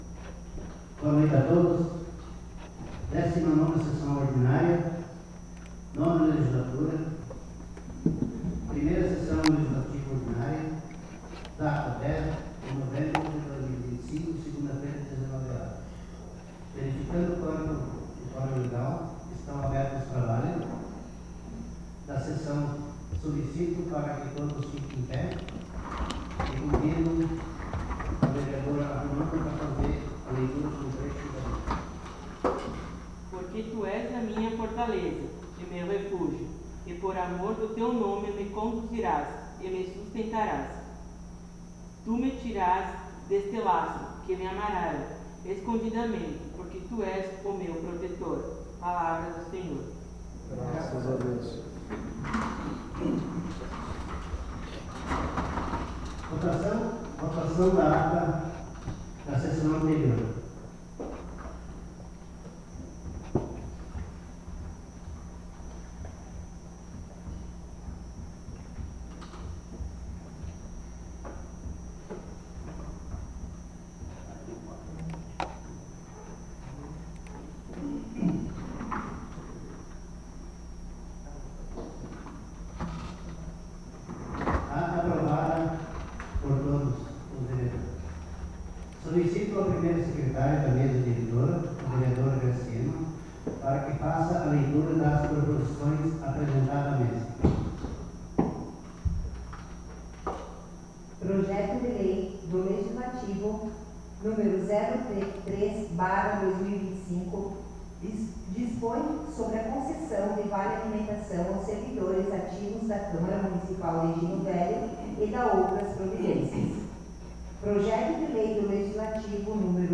Audio 19ª Sessão 10.11.2025 — Câmara Municipal